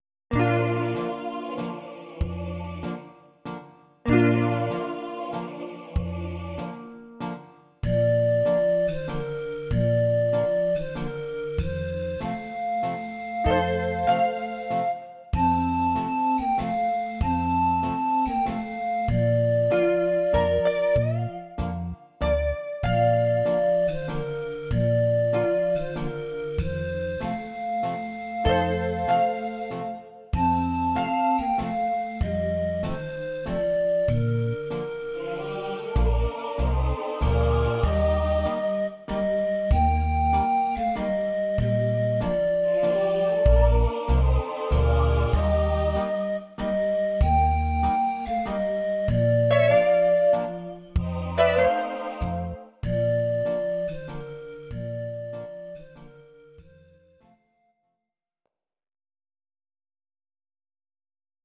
Audio Recordings based on Midi-files
Oldies, German, Duets, 1960s